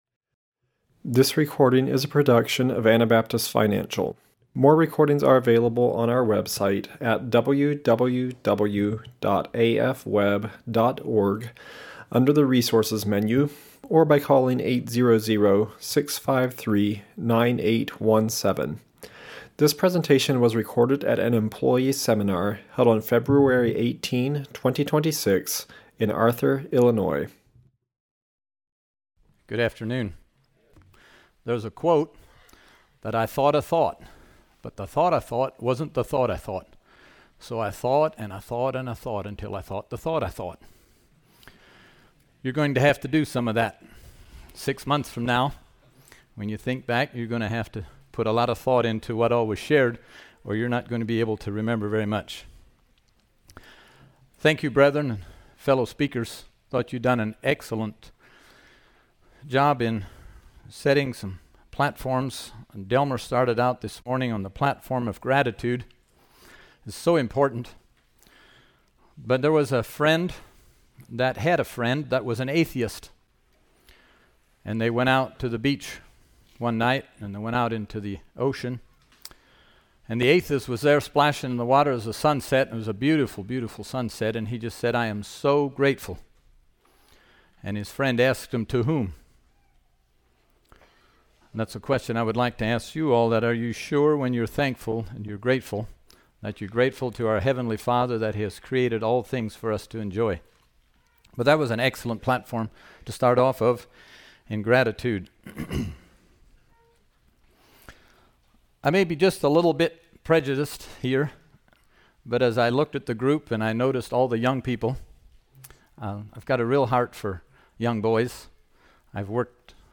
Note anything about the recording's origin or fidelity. Illinois Employee Seminar 2026